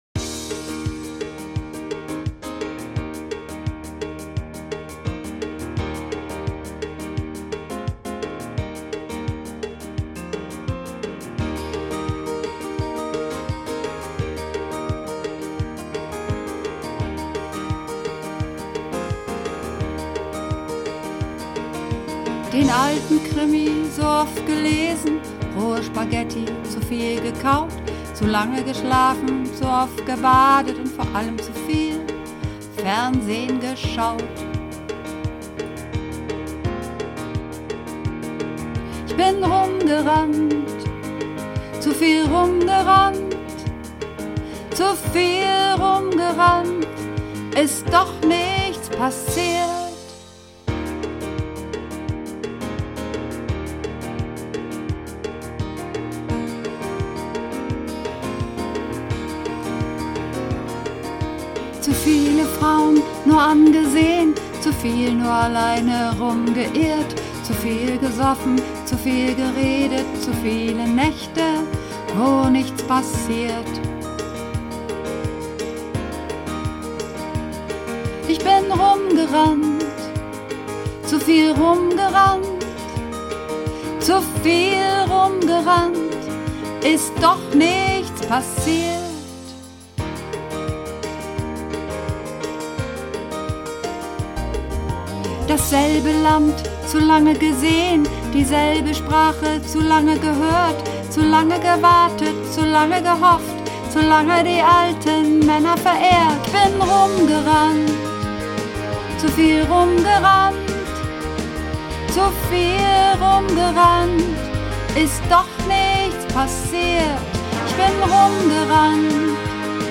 Übungsaufnahmen - Langeweile
Langeweile (Sopran)
Langeweile__4_Sopran.mp3